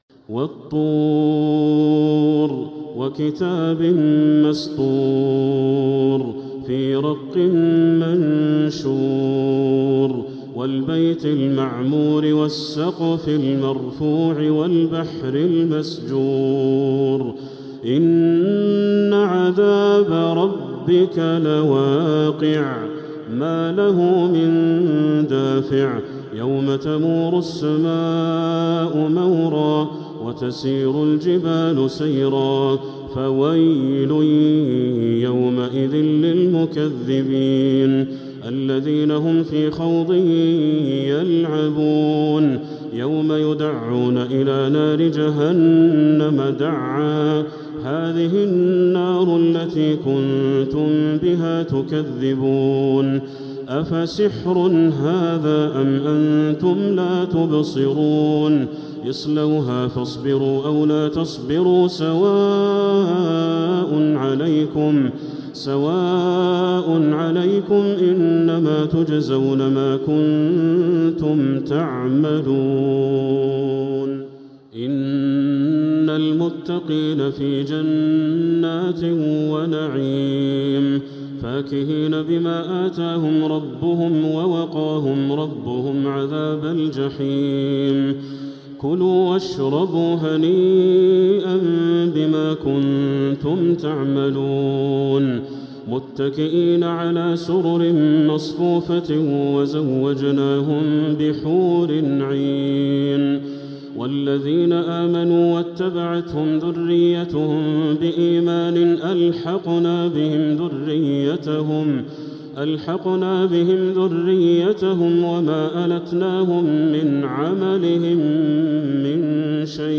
سورة الطور كاملة | رمضان 1446هـ > السور المكتملة للشيخ بدر التركي من الحرم المكي 🕋 > السور المكتملة 🕋 > المزيد - تلاوات الحرمين